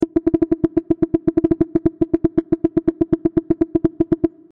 typing.MP3